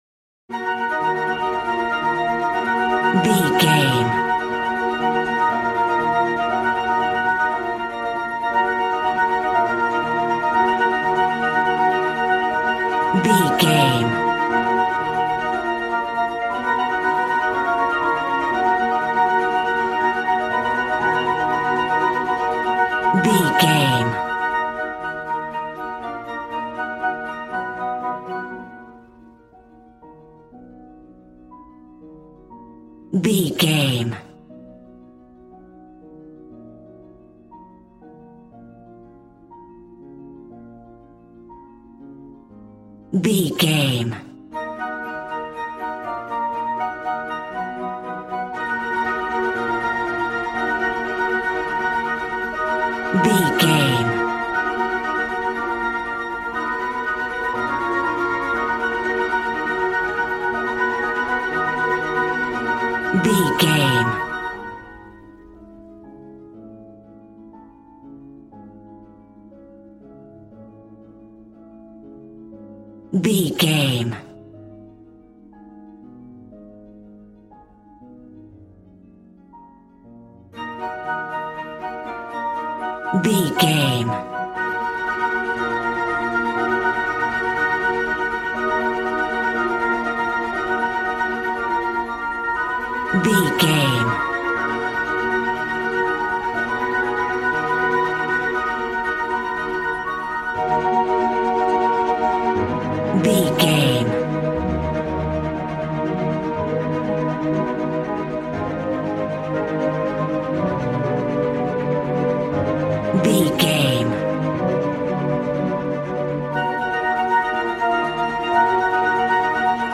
Regal and romantic, a classy piece of classical music.
Ionian/Major
G♭
strings
violin
brass